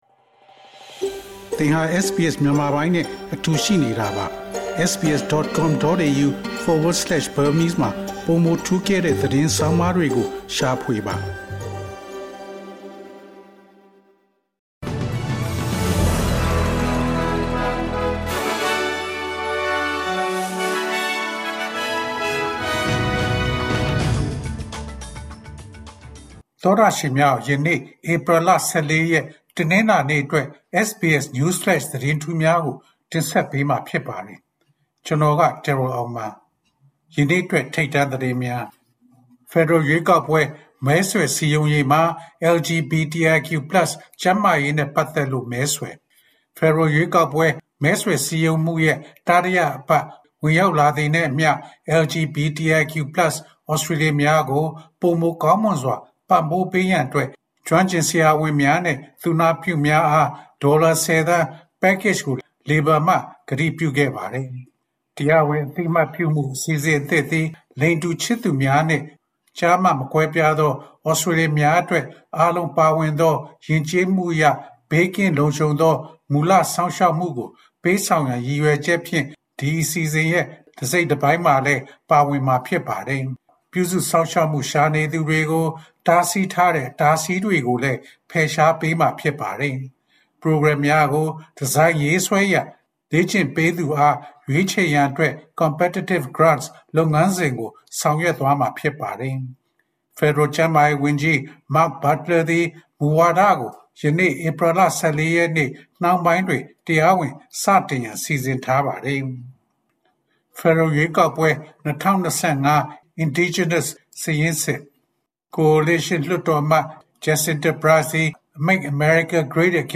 SBS မြန်မာ ၂၀၂၅ ခုနှစ် ဧပြီ ၁၄ ရက် နေ့အတွက် News Flash သတင်းများ။